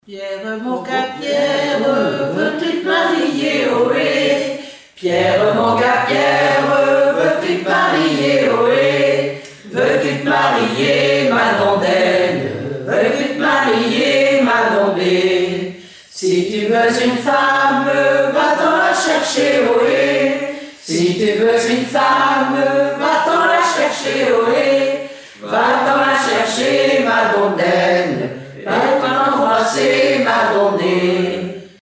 Rondes à baisers et à mariages fictifs
danse-jeu : danse du tapis
Chansons et formulettes enfantines
Pièce musicale inédite